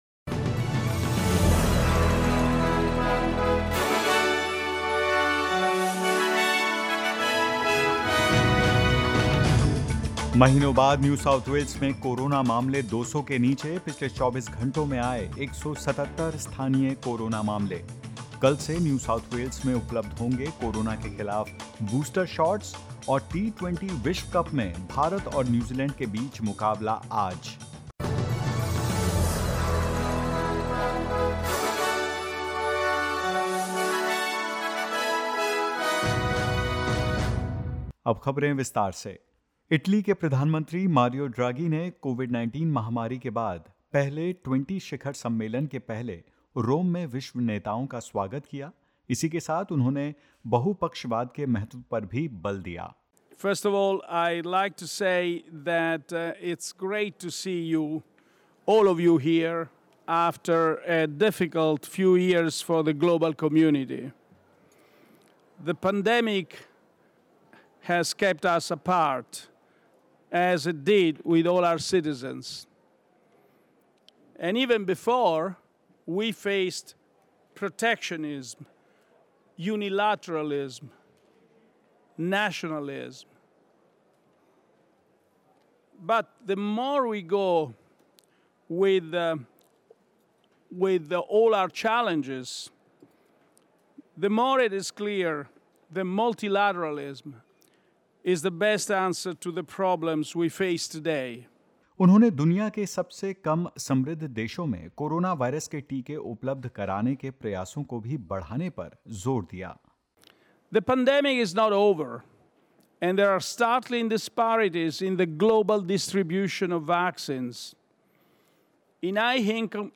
In this latest SBS Hindi News bulletin of Australia and India: Leaders of the world's 20 biggest economies meet to endorse a global tax deal and discuss coronavirus vaccines for poorer nations; Tributes for Australian showbusiness legend, Bert Newton, who's died aged 83 and more.